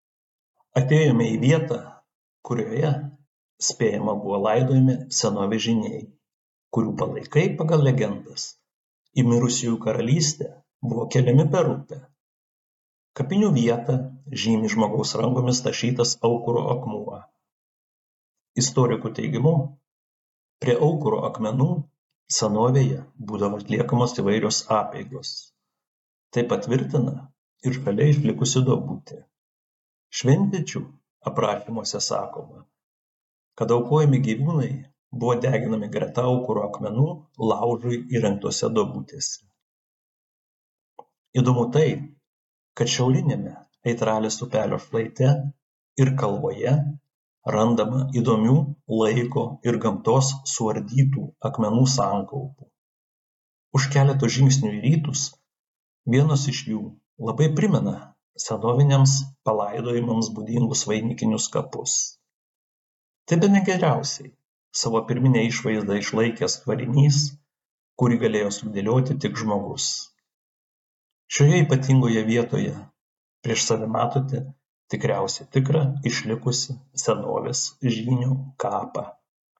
Jūs klausote miškininko pasakojimo